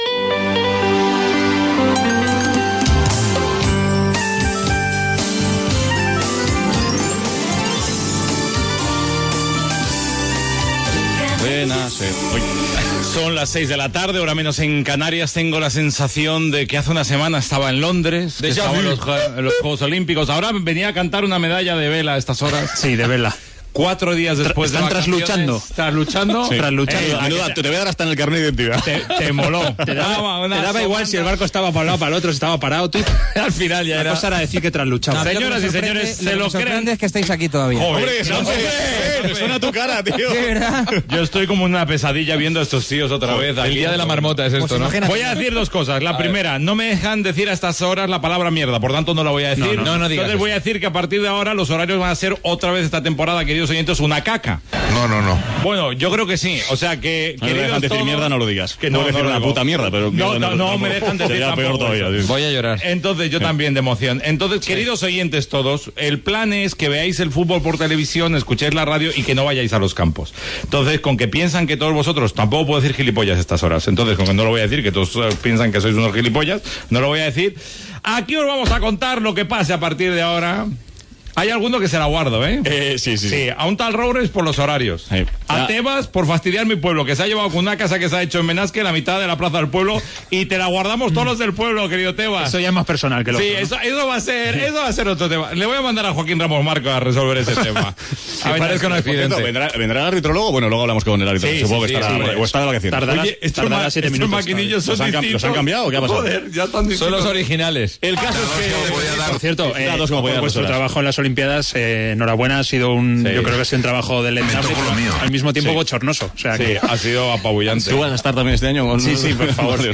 Presentació inicial amb les marques publicitàries i els noms de l'equip.
Gènere radiofònic Esportiu